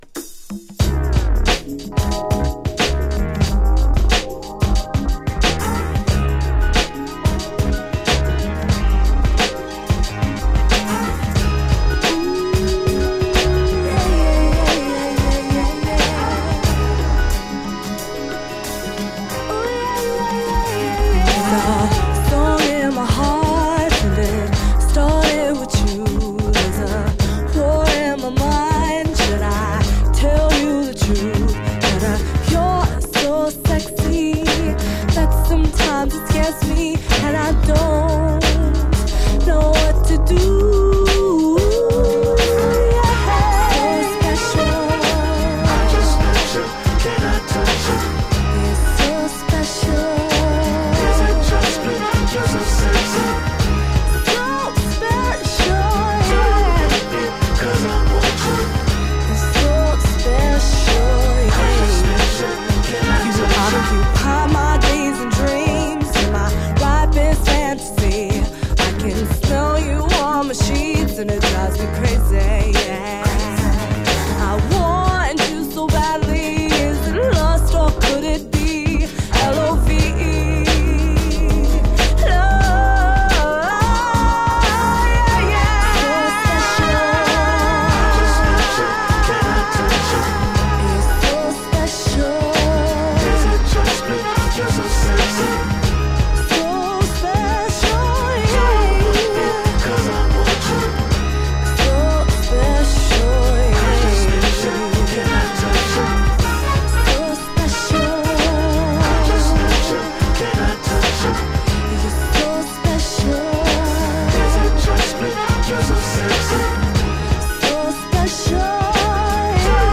次世代のコズミックファンクに満ちた絶品アルバム！